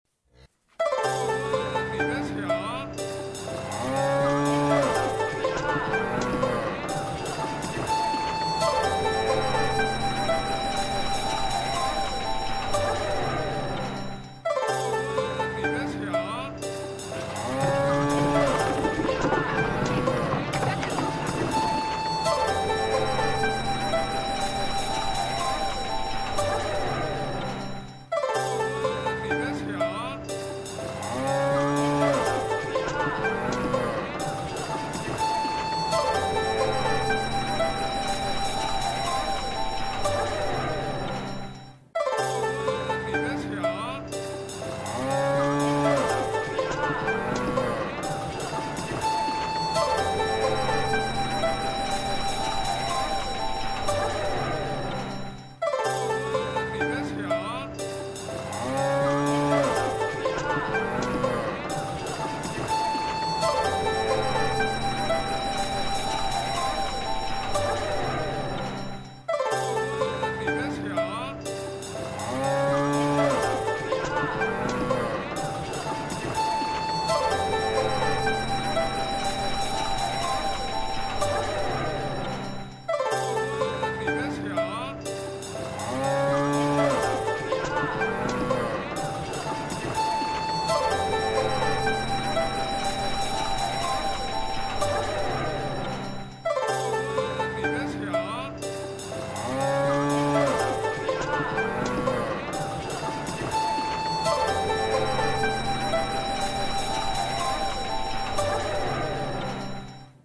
前奏片段